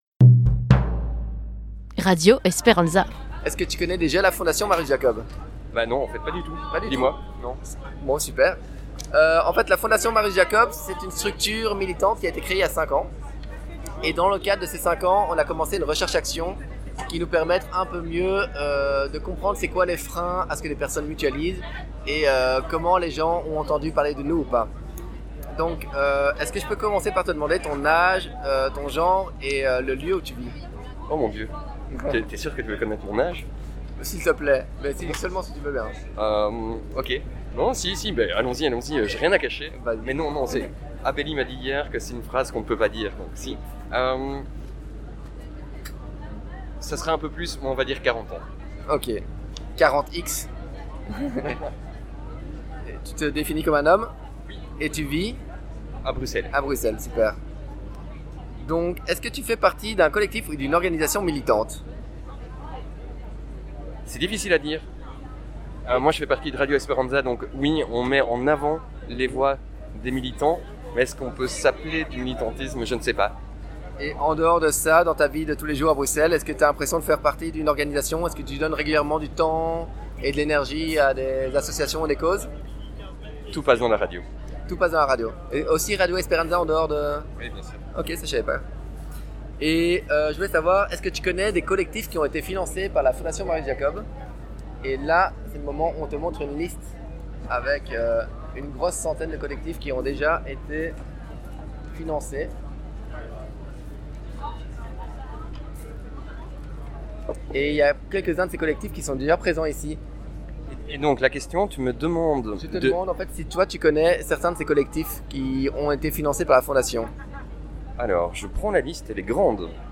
Interview d'un membre de la Fondation Marius Jacob